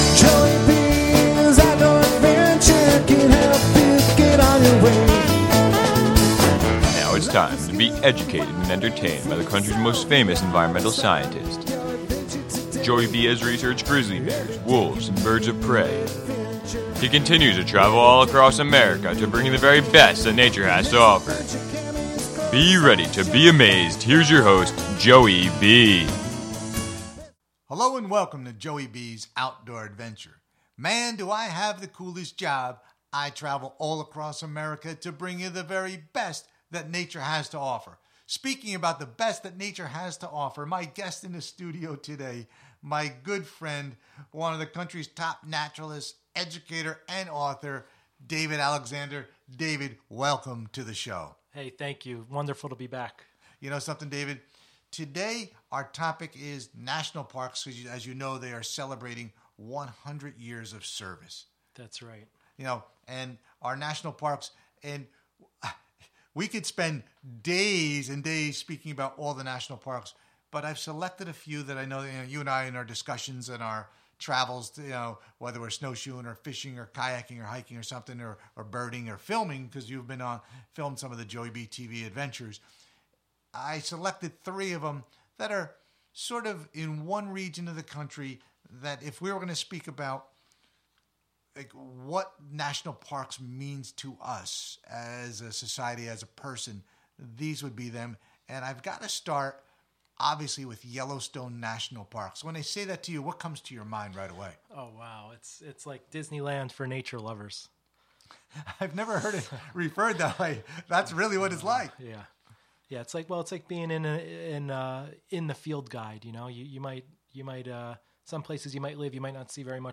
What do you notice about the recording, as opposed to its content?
In the studio today is Naturalist